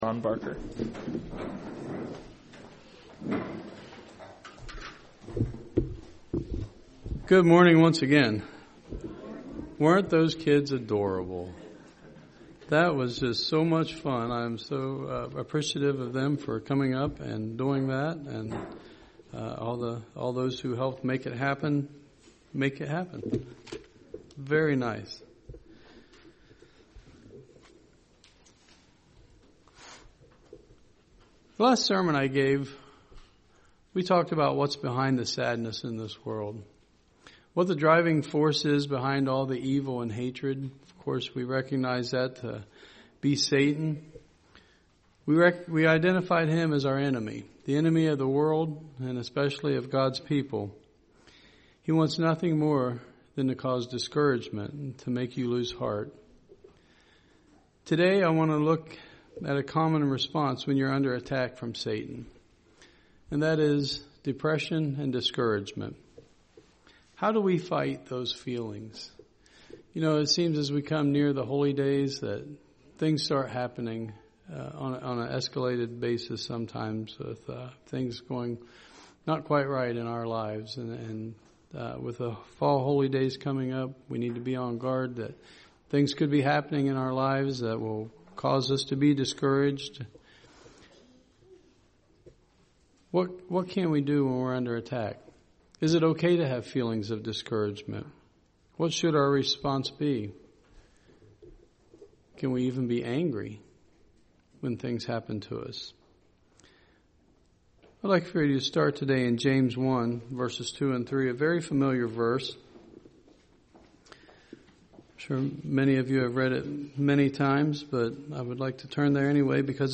Sermons
Given in Dayton, OH